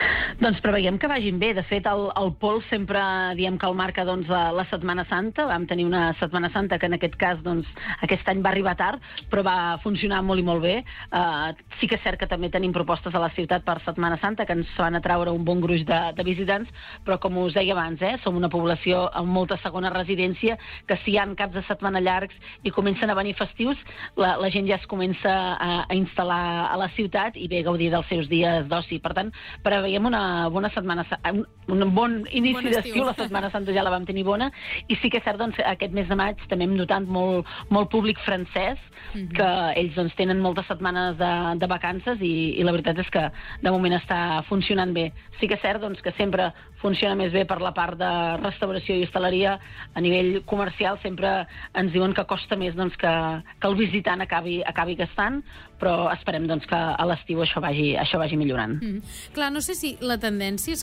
Entrevistes